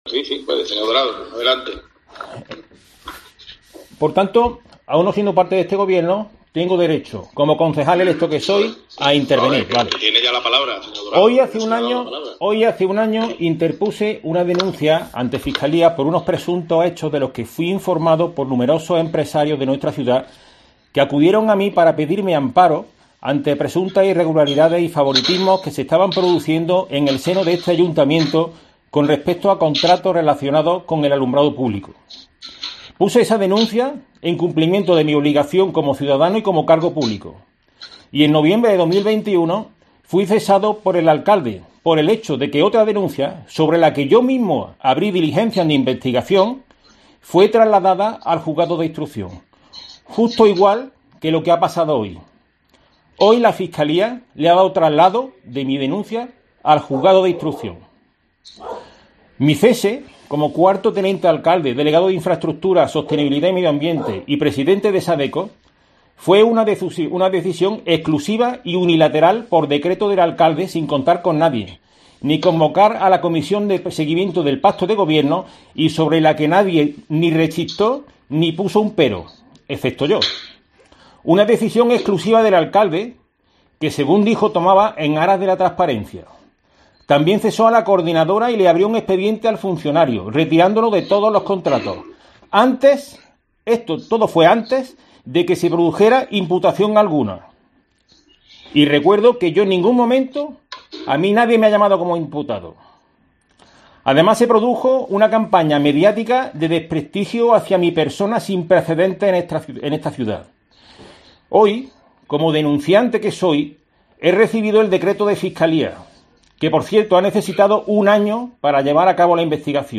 Escucha la intervención de David Dorado durante el pleno de julio en el Consistorio